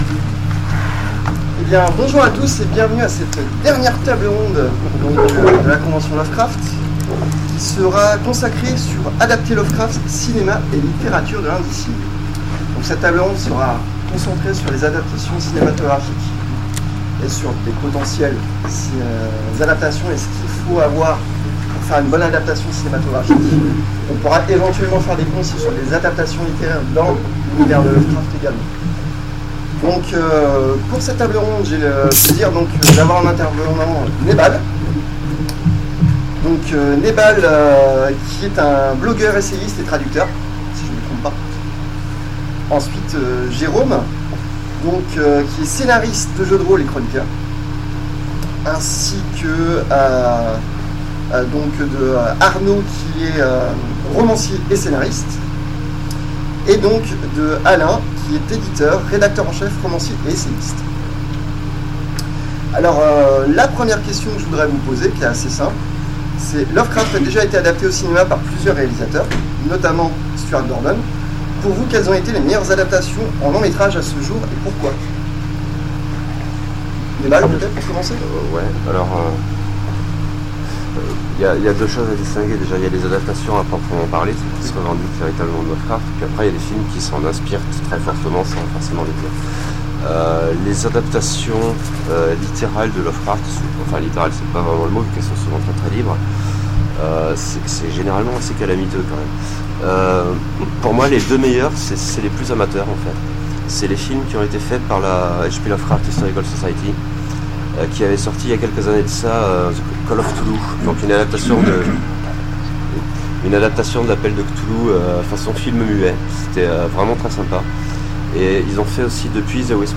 Necronomicon 2015 : Conférence Adapter Lovecraft